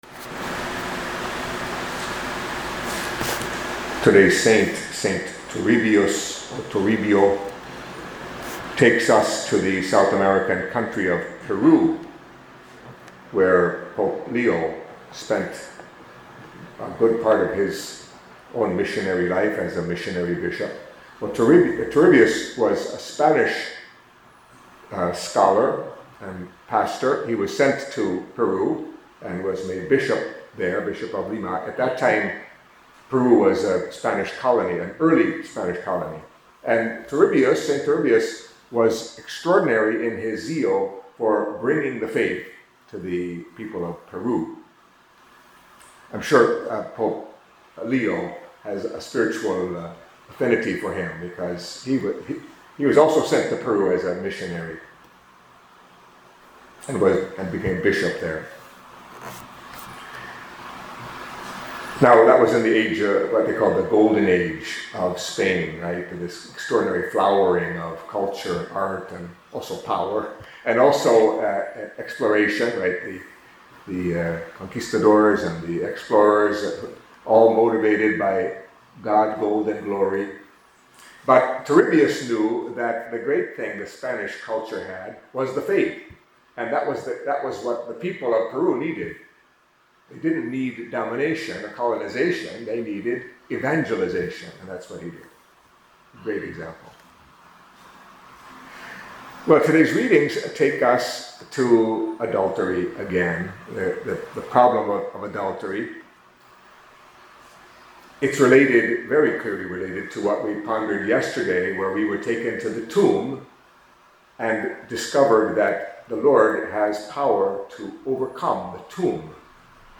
Catholic Mass homily for Monday of the Fifth Week of Lent